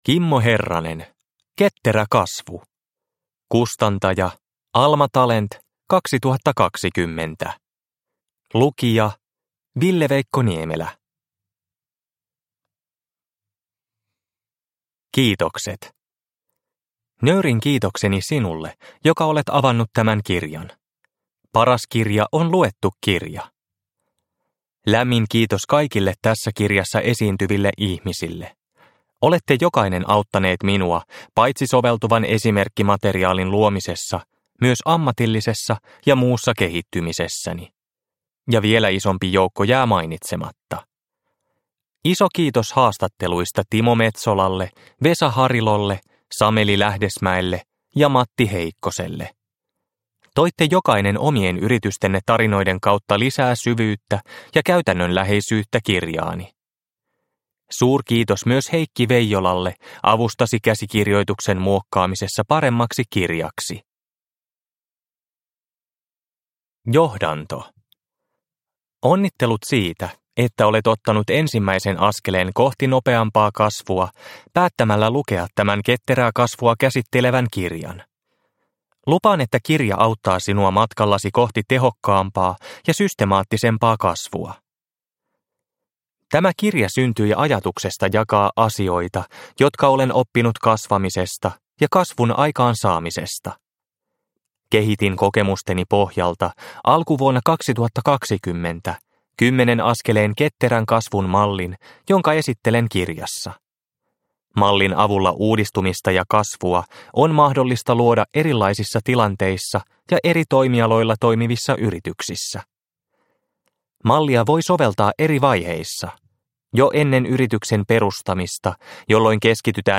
Ketterä kasvu – Ljudbok – Laddas ner